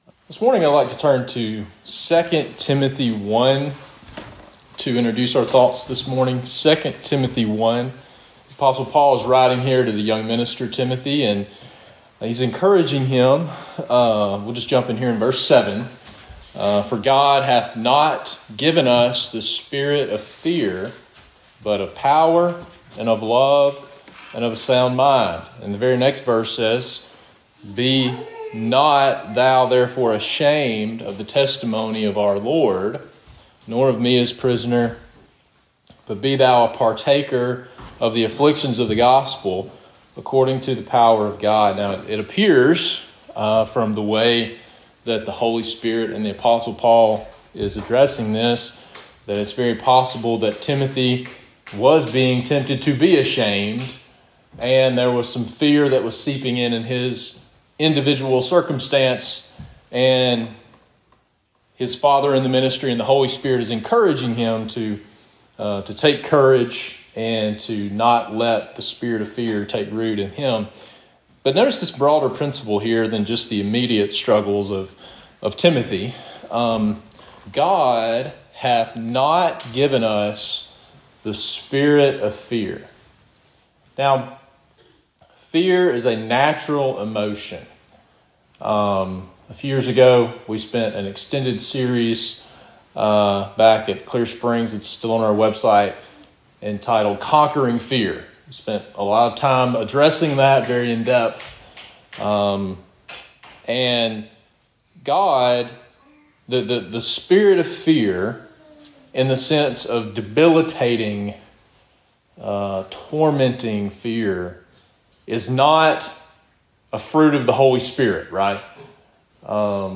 Preached at Gum Log PBC (Bailey, MS) on 3/16/2026.